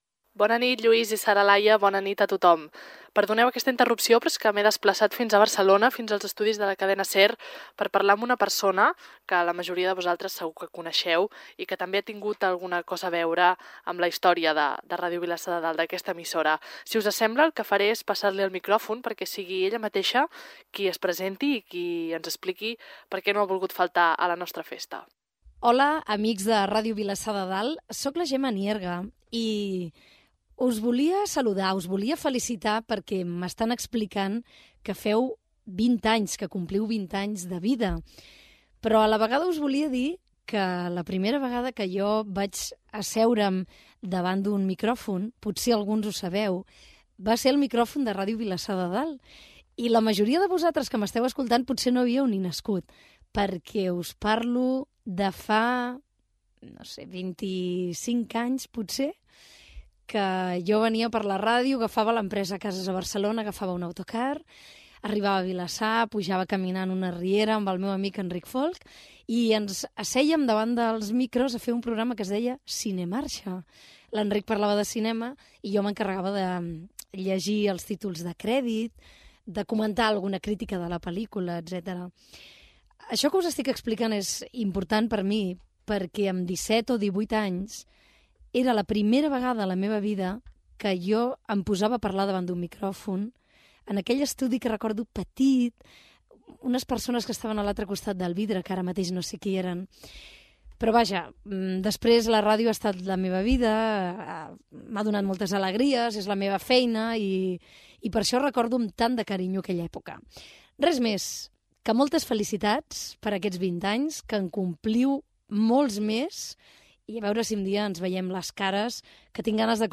Paraules de la periodista Gemma Nierga, des dels estudis de Ràdio Barcelona, amb motiu del 20è aniversari de Ràdio Vilassar de Dalt.